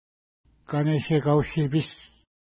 Pronunciation: ka:neʃeka:w-ʃi:pi:s
Pronunciation